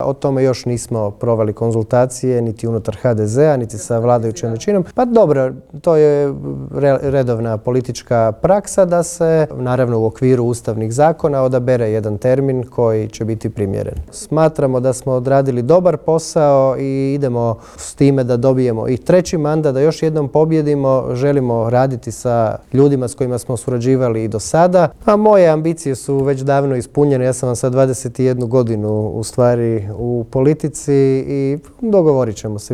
Otkrio nam je u Intervjuu tjedna Media servisa uoči održavanja sjednice Odbora za gospodarstvo upravo na temu prodaje plina višestruko ispod tržišne cijene.